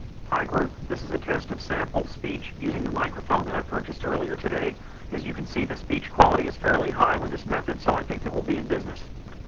Here we simply work out the AR coefficients and throw white noise through the prediction error filter. As you can see by playing the sound file below the quality of the speech is not terribly good. Although we can sort of make out what the person is saying it is not clear who is actually speaking.
White Noise Excited Speech